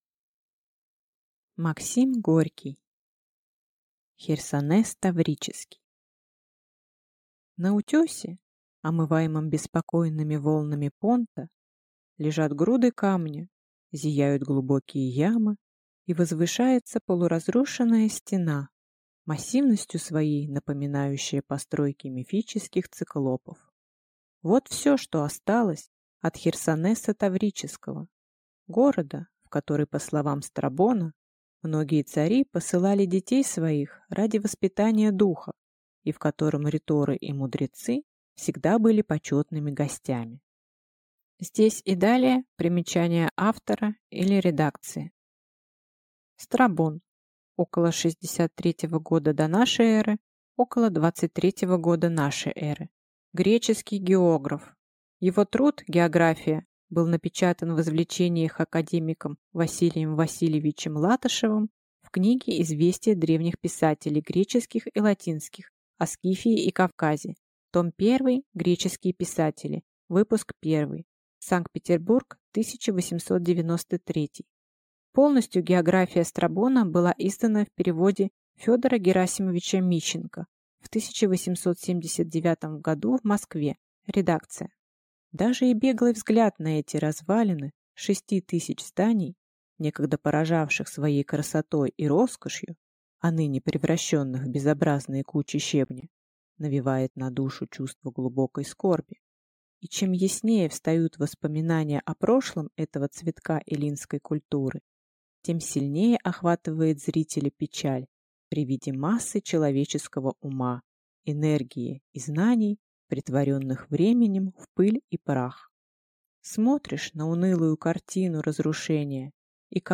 Аудиокнига Херсонес Таврический | Библиотека аудиокниг